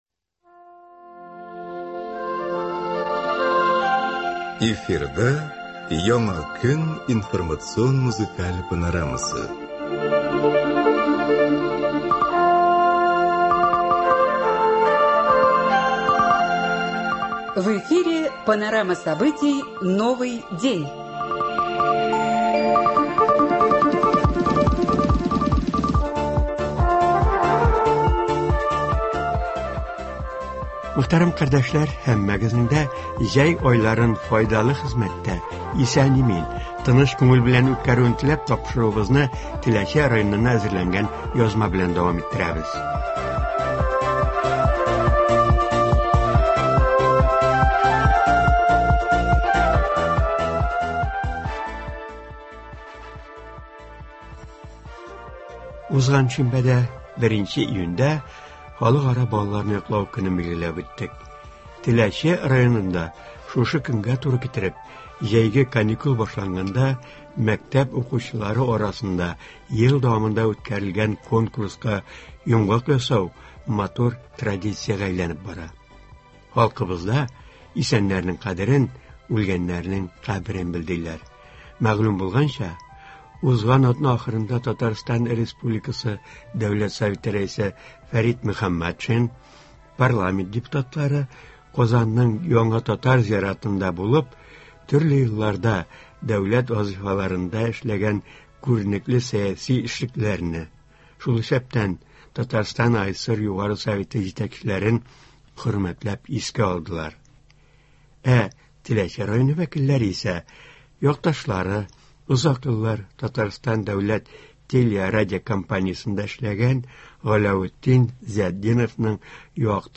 Туры эфир (03.06.24)
Чираттагы тапшыруда шушы чарадан репортаж бирелә.